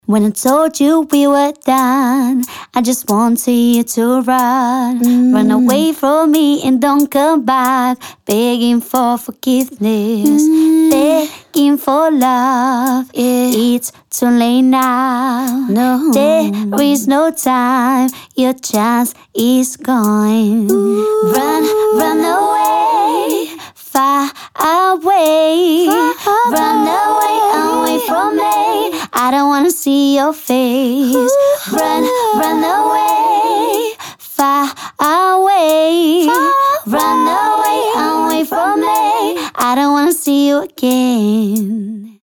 除了完整的无伴奏合唱之外，您还将获得更多漂亮的广告素材，谐音单音和短语。